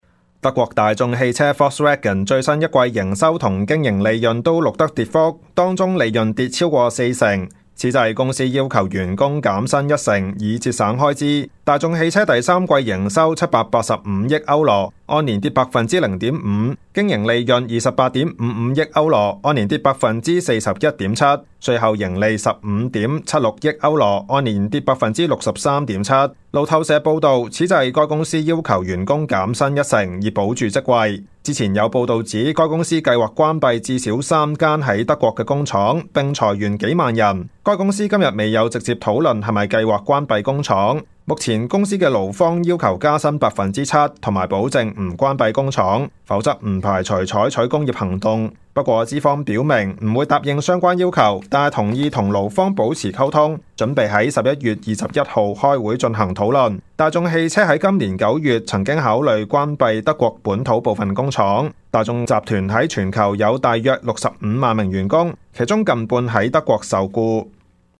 Canada/World News 全國/世界新聞
news_clip_21099.mp3